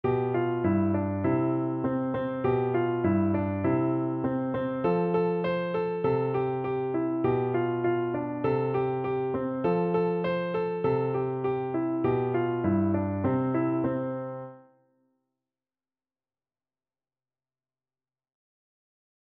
No parts available for this pieces as it is for solo piano.
2/4 (View more 2/4 Music)
Piano  (View more Beginners Piano Music)